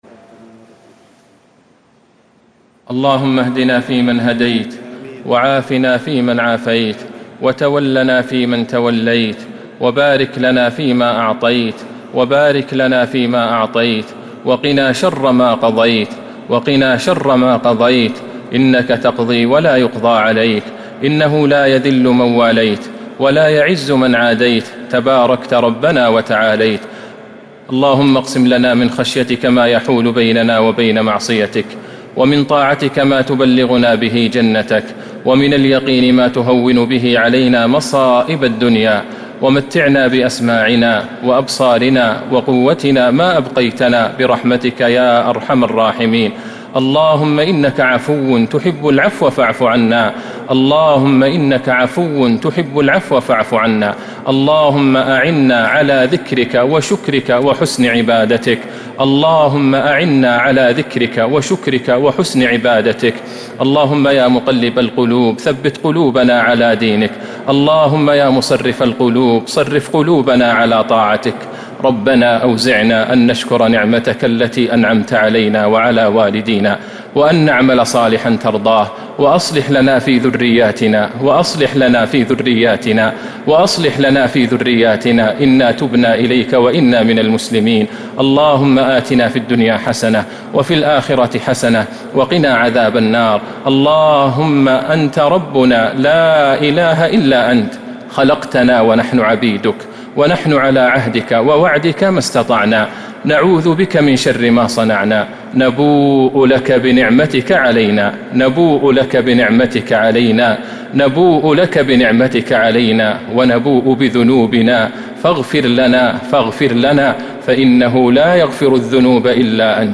دعاء القنوت ليلة 10 رمضان 1442هـ | Dua for the night of 10 Ramadan 1442H > تراويح الحرم النبوي عام 1442 🕌 > التراويح - تلاوات الحرمين